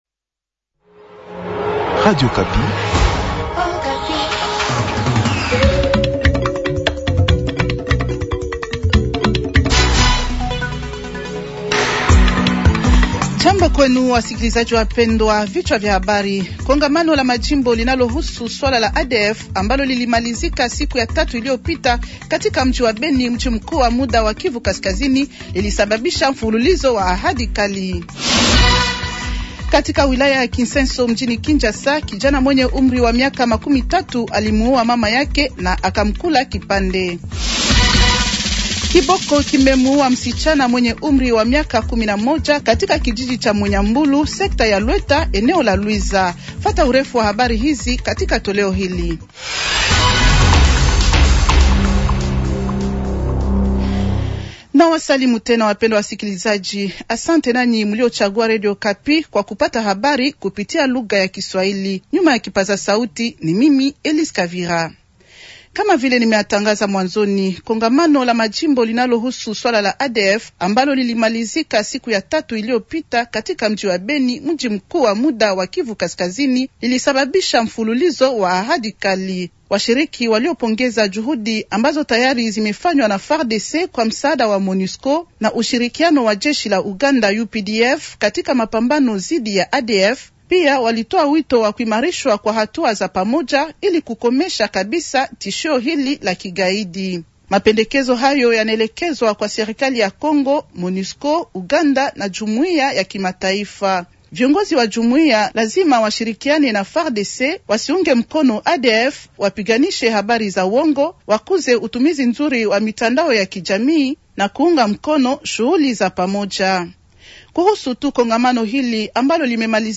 Journal Swahili de Vendredi matin 270226